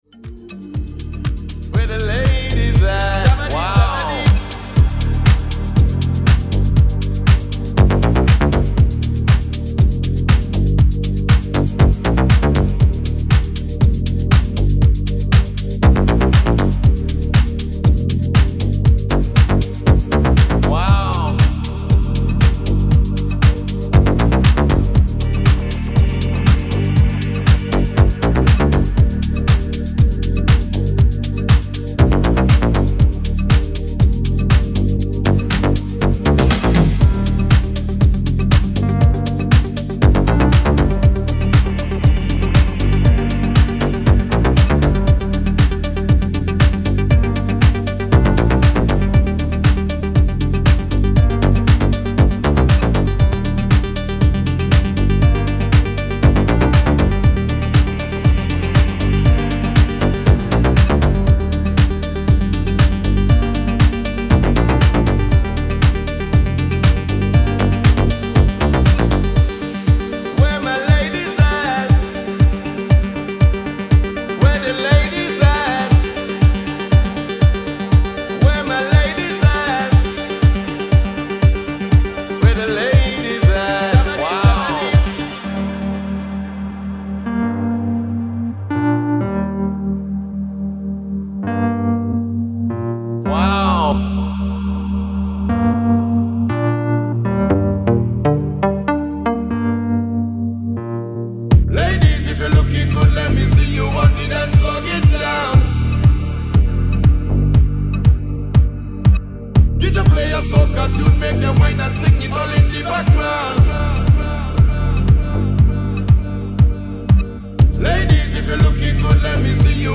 Chilout_10.mp3